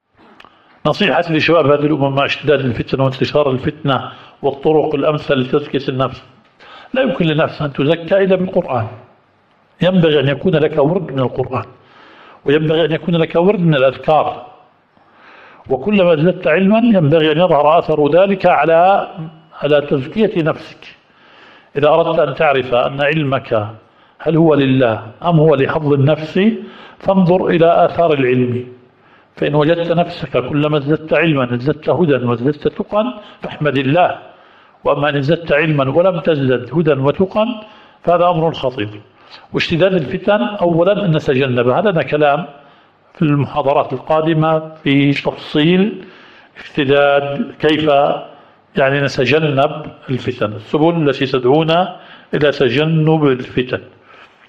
الدورة الشرعية الثالثة للدعاة في اندونيسيا
المحاضرة الرابعة.